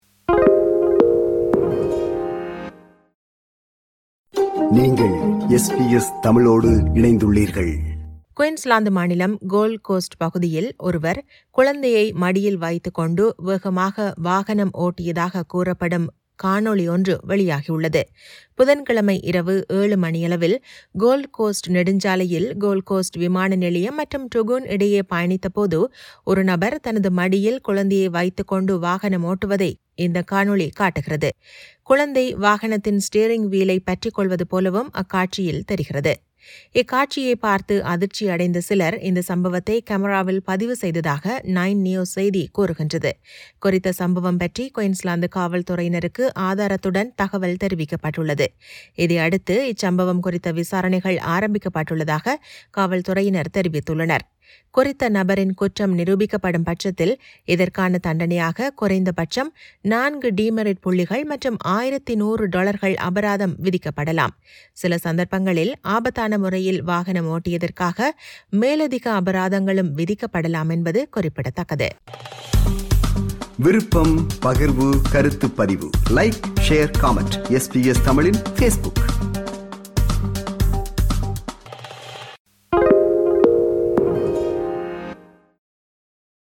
குயின்ஸ்லாந்து மாநிலம் கோல்ட் கோஸ்ட் பகுதியில் குழந்தையை மடியில் வைத்துக்கொண்டு வேகமாக வாகனம் ஓட்டியதாகக் கூறப்படும் ஒருவர் தொடர்பில் காவல்துறையினர் விசாரணைகளை ஆரம்பித்துள்ளனர். இதுகுறித்த செய்தி விவரணத்தை